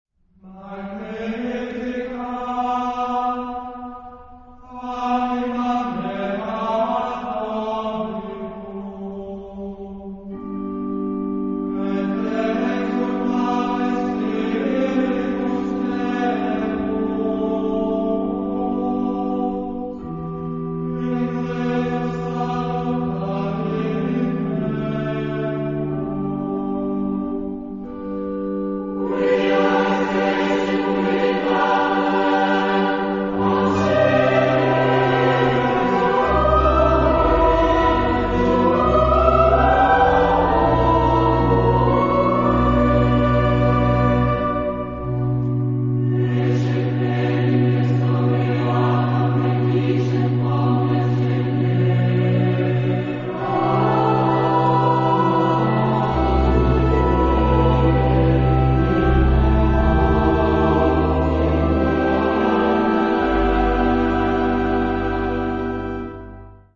Género/Estilo/Forma: Sagrado ; Magnificat
Tipo de formación coral: SATB  (4 voces Coro mixto )
Tonalidad : re (centro tonal)
Consultable bajo : 20ème Sacré Acappella